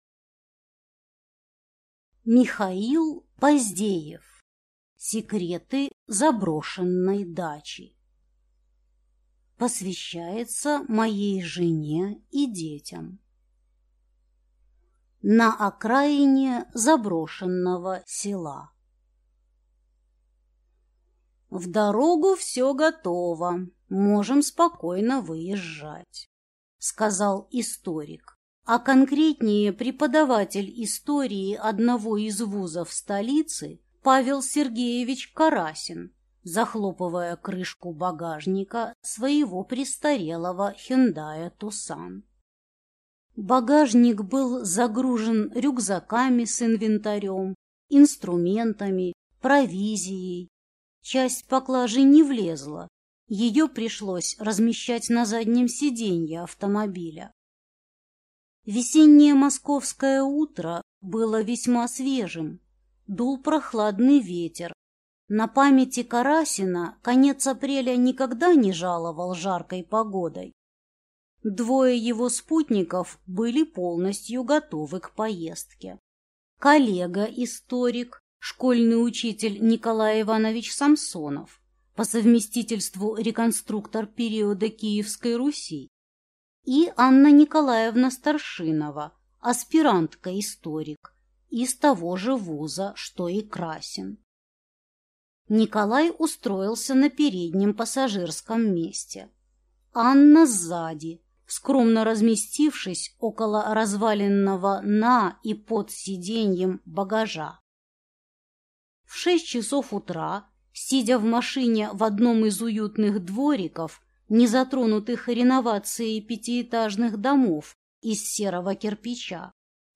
Аудиокнига Секреты Заброшенной дачи | Библиотека аудиокниг